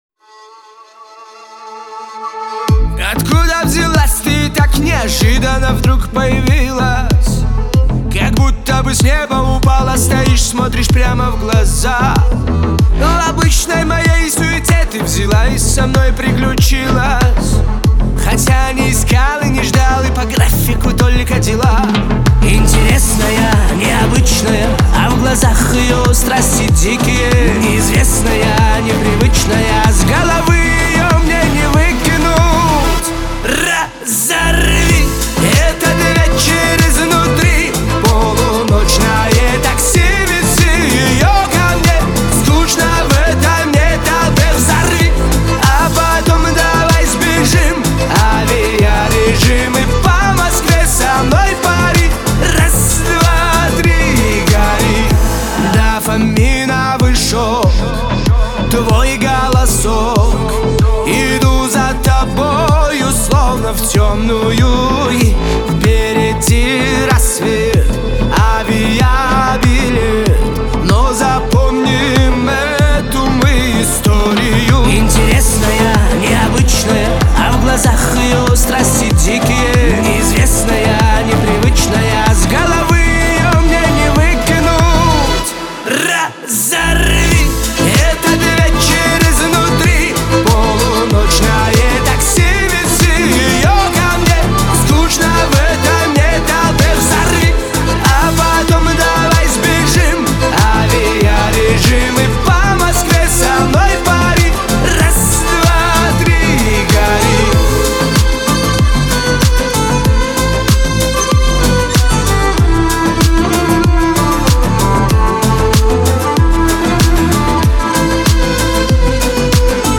Жанр: Русская музыка